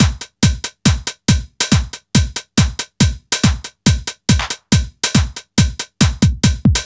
NRG 4 On The Floor 039.wav